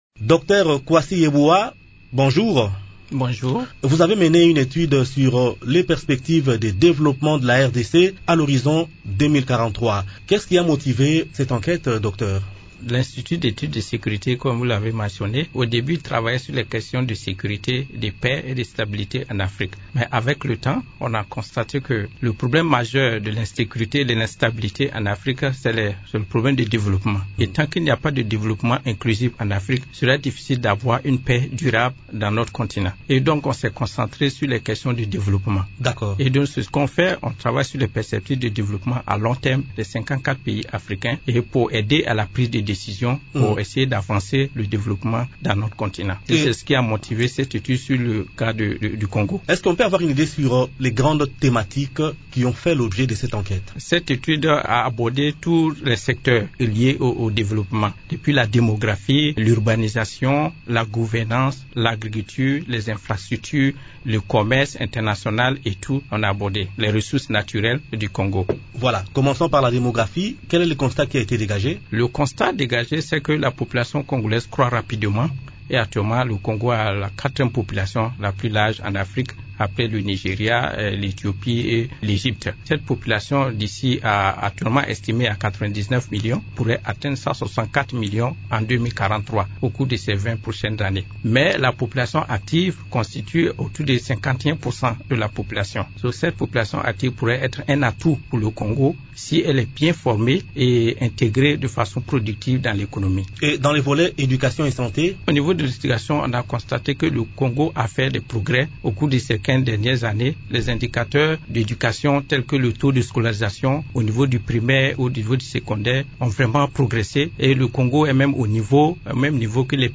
L'invité du jour, Émissions / Paix, Kindu, Maniema, Journée internationale de la paix